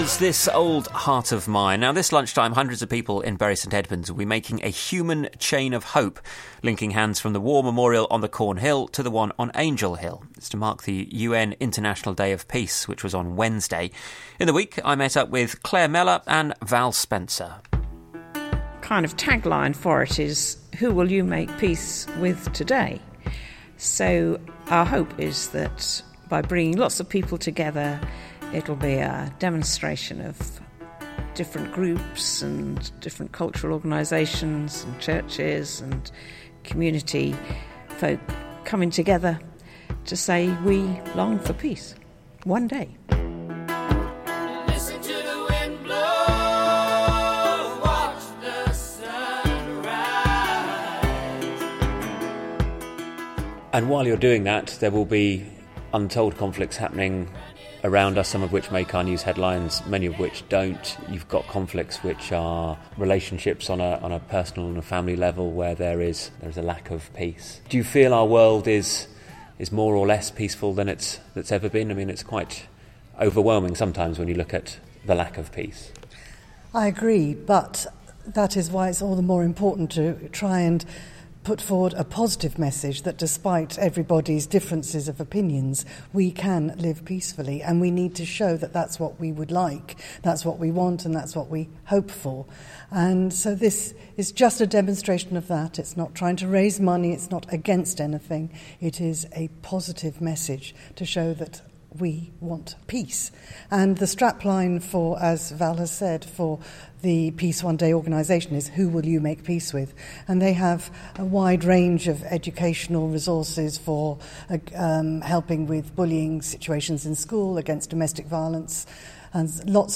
BBC Radio Suffolk interviewed